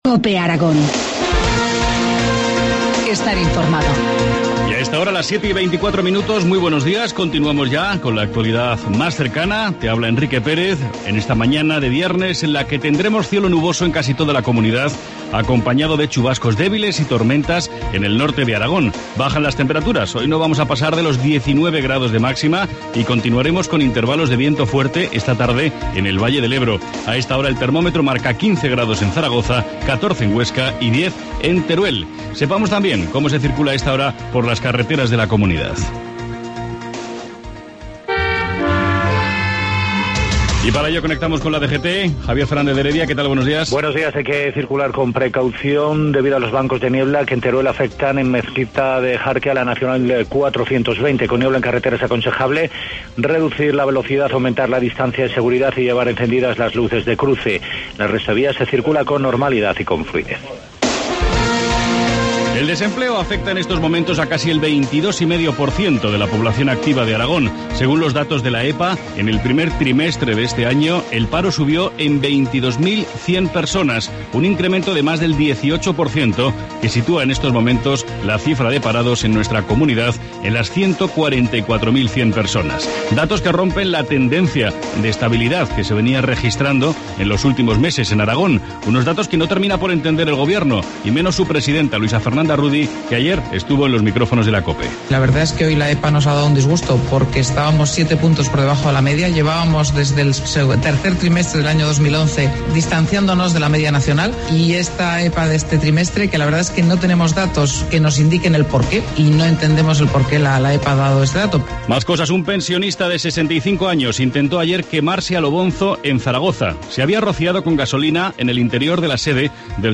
Informativo matinal, viernes 26 de abril, 7.25 horas